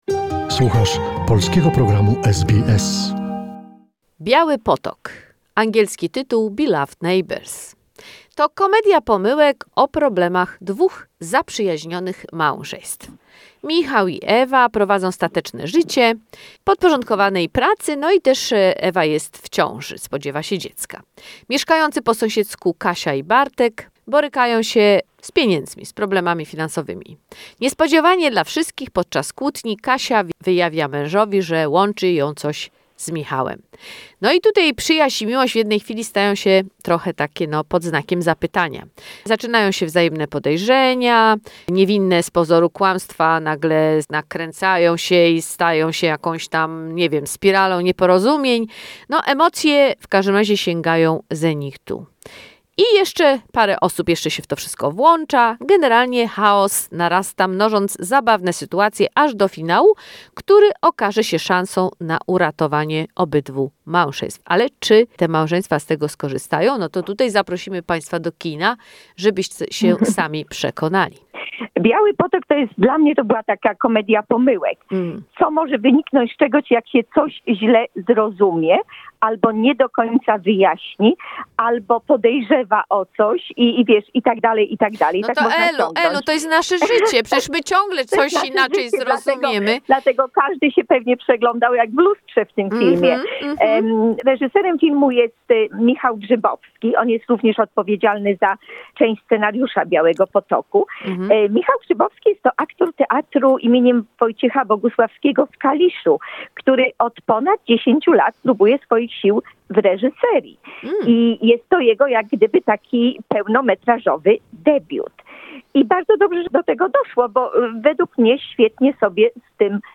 Movie review "Beloved neighbours"